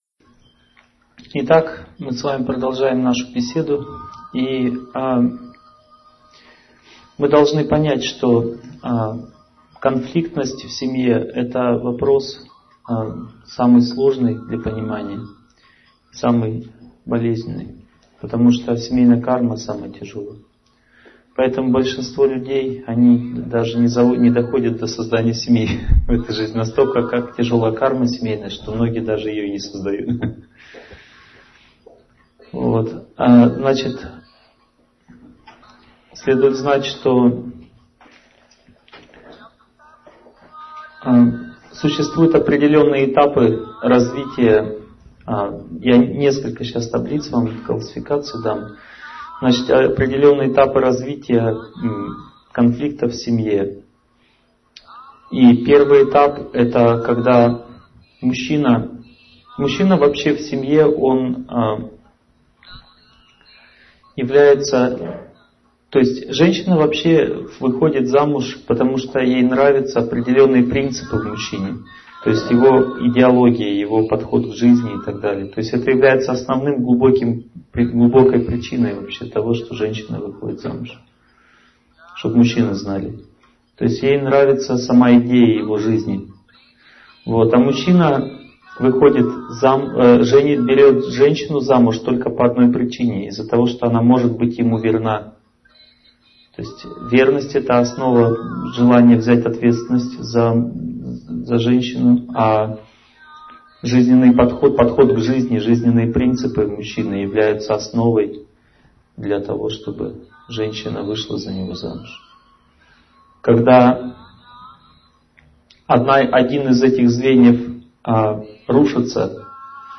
Аудиокнига Решение конфликтных ситуаций в семье | Библиотека аудиокниг